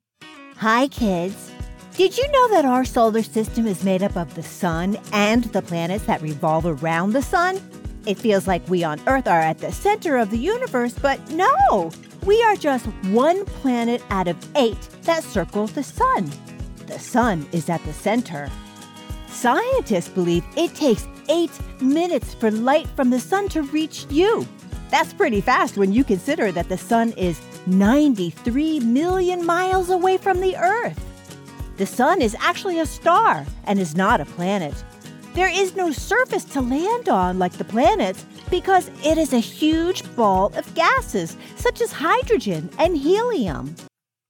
medical, authoritative, trustworthy,